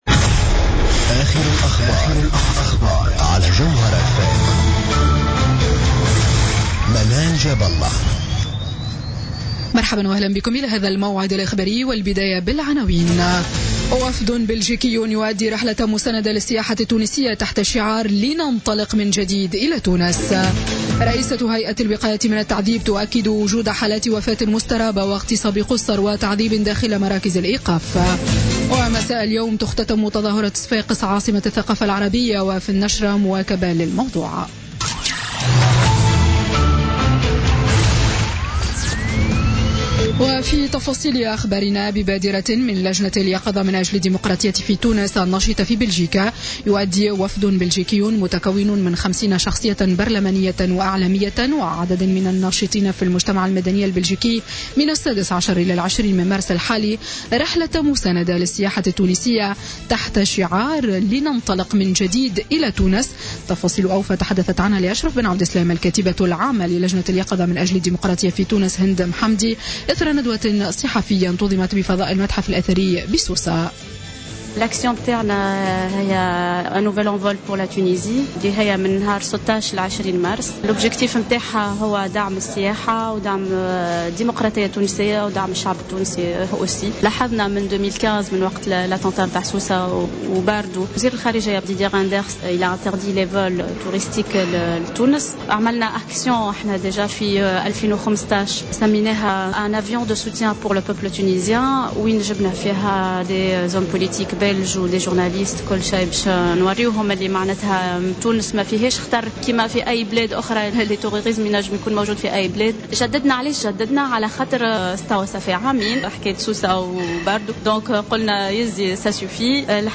نشرة أخبار السابعة مساء ليوم الجمعة 17 مارس 2017